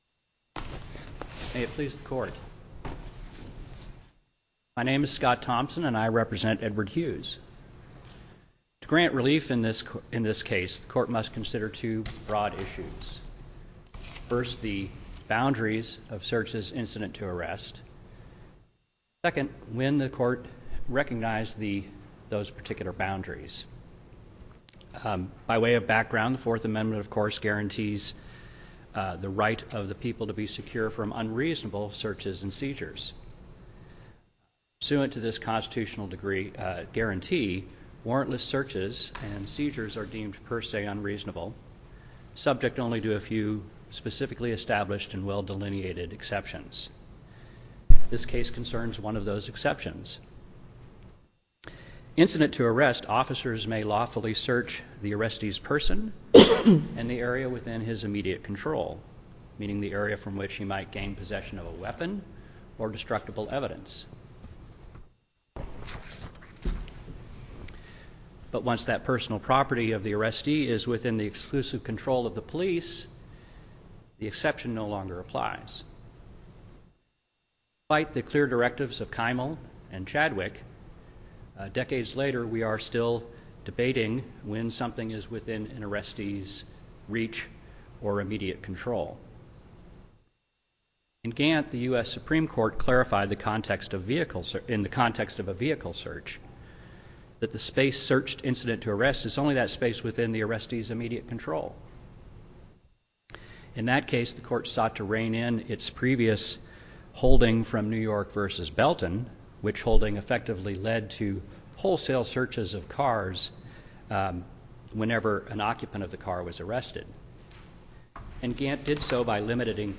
Constitutional challenge to search and seizure in drug possession case Listen to the oral argument